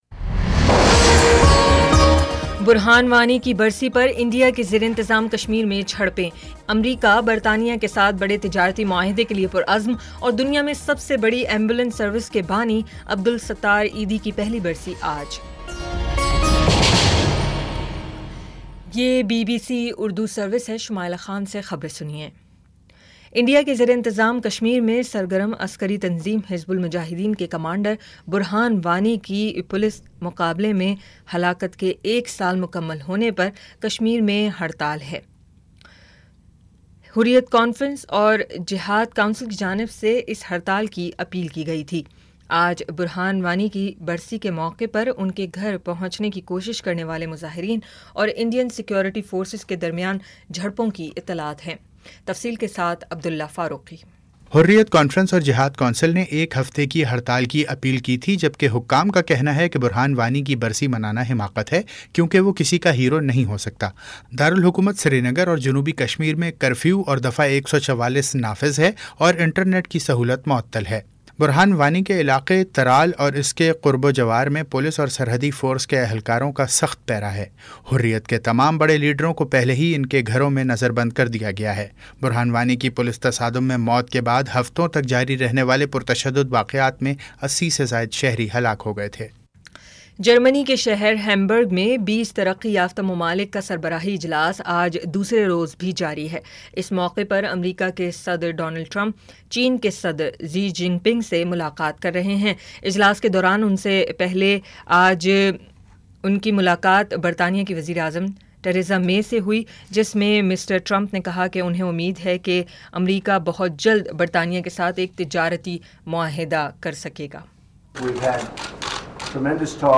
جولائی 08 : شام پانچ بجے کا نیوز بُلیٹن